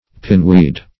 pineweed - definition of pineweed - synonyms, pronunciation, spelling from Free Dictionary
Pineweed \Pine"weed`\, n. (Bot.)